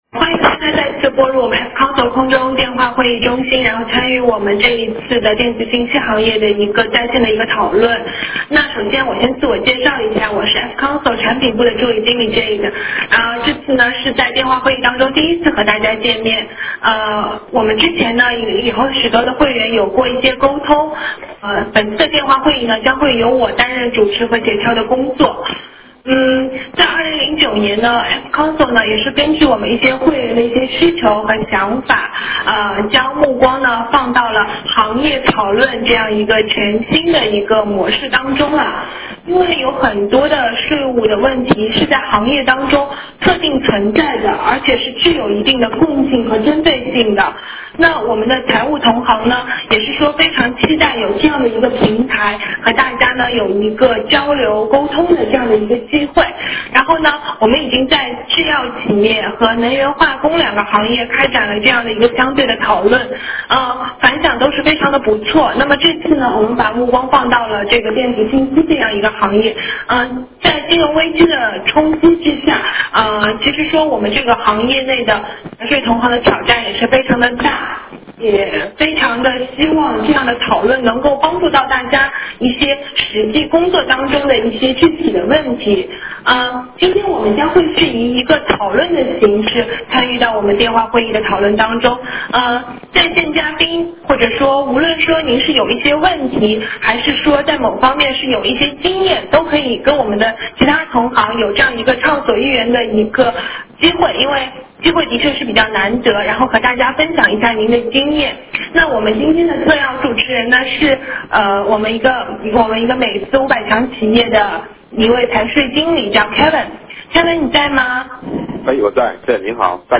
会议时间：2009年4月14日15：00-17：00 会议形式：在线电话会议 会议安排： Part1: 在线各位讨论嘉宾将根据我们的讨论话点轮流进行讨论，不仅提出自己的问题与困惑，更多的将分享其现行的做法与经验。 Part2：其他线上参会者Q&A环节，全方位与讨论嘉宾互动。